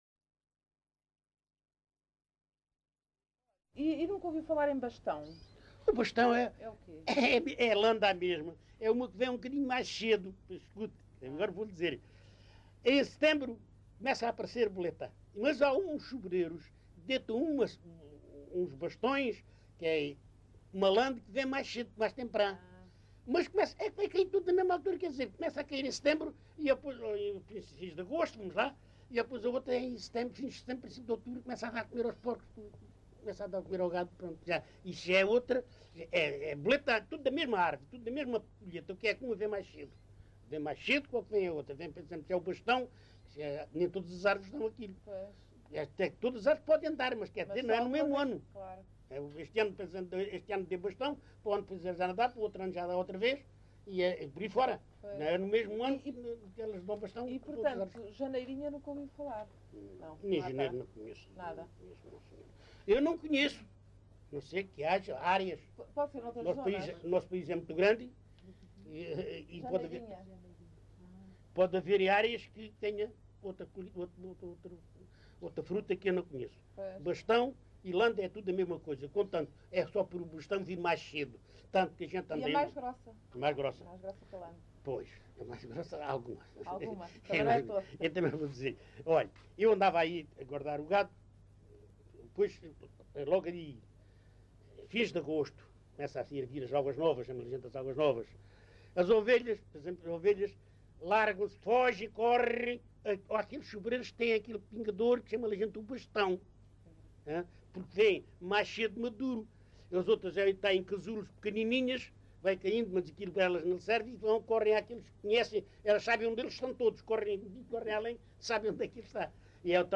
LocalidadeLavre (Montemor-o-Novo, Évora)